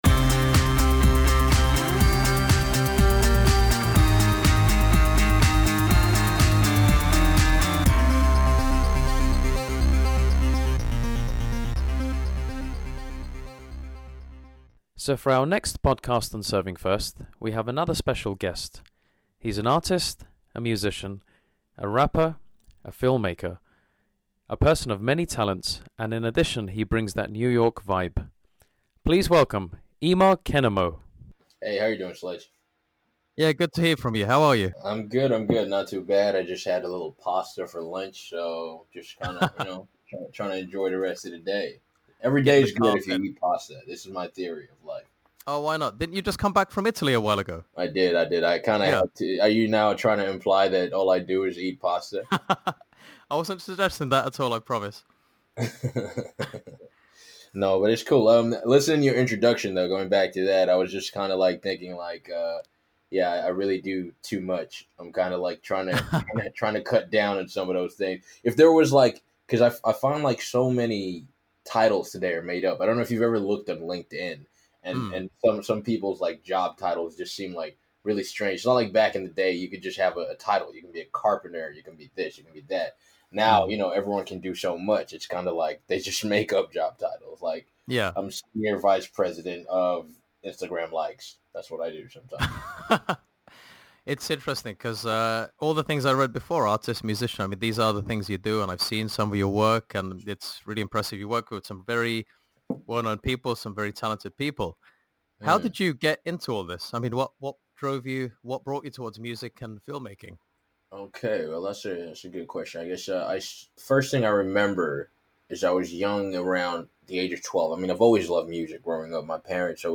Serving First Episode 2: Interview